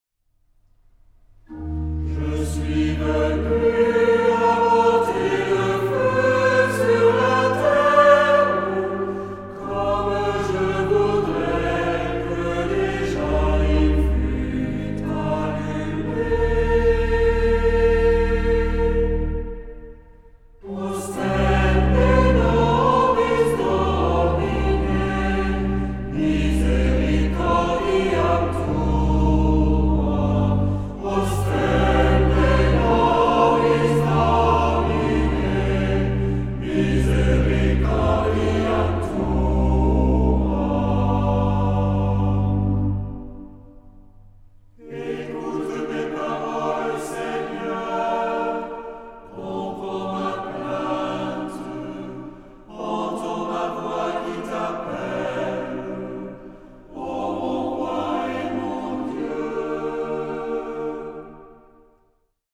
Genre-Style-Form: troparium ; Psalmody
Mood of the piece: collected
Type of Choir: SATB  (4 mixed voices )
Instruments: Organ (1) ; Melody instrument (optional)
Tonality: C minor ; E flat major